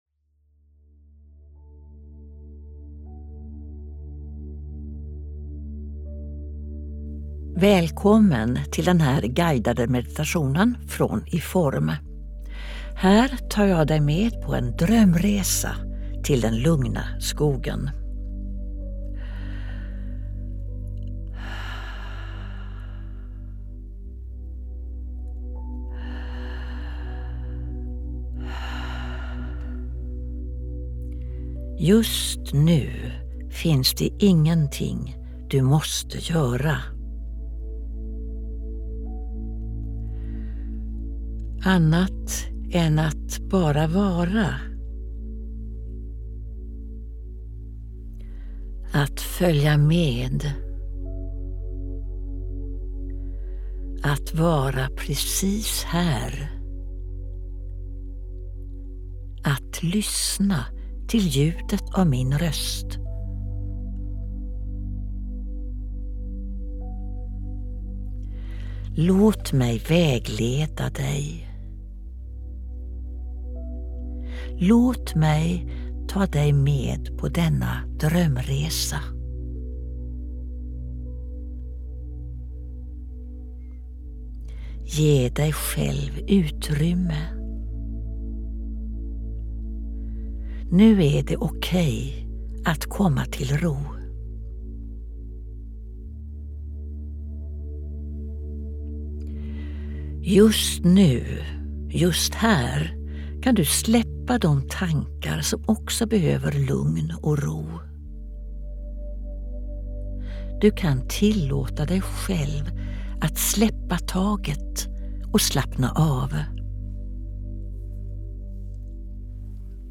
Guidad sömnmeditation: Drömresa till den lugna skogen
Det här får du i ljudfilen: Meditationen varar i 30 minuter. Den tar dig med på en resa till skogens lugna skönhet.
De sista fem minuterna innehåller inget prat, utan är bara en fortsättning på det lugna ljudspåret.